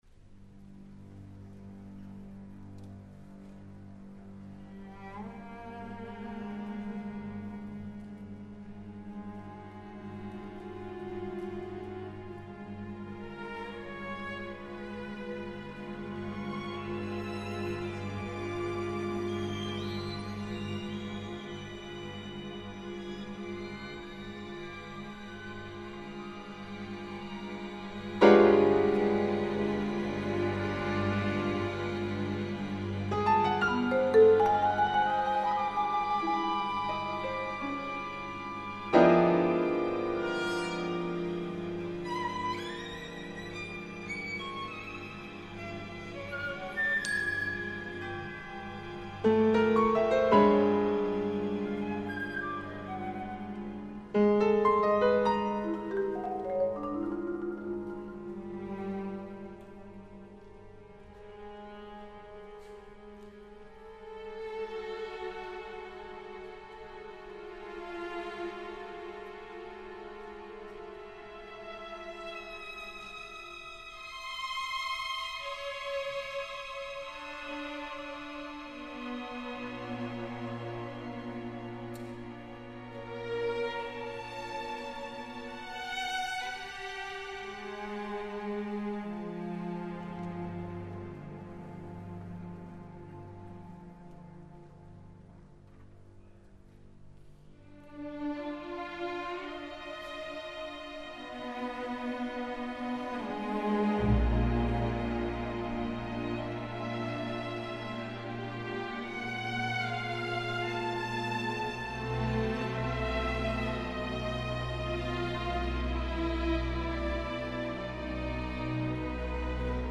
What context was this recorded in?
Recorded live in concert